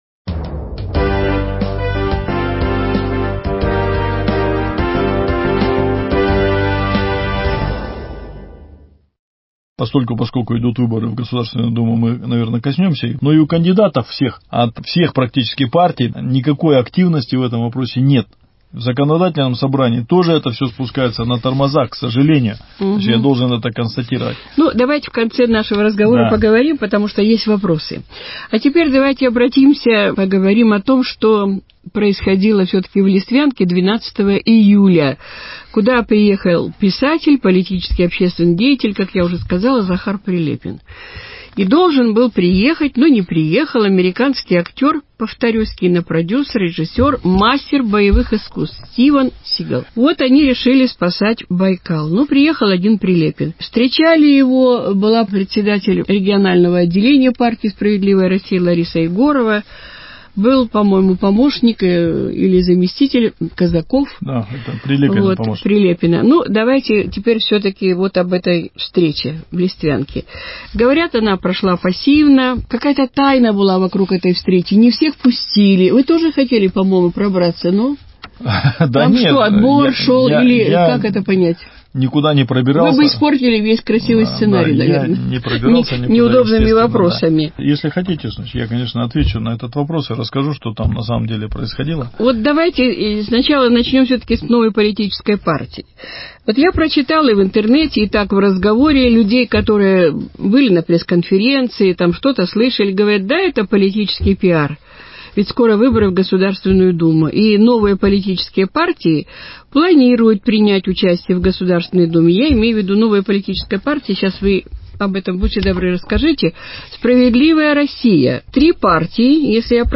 Продолжение беседы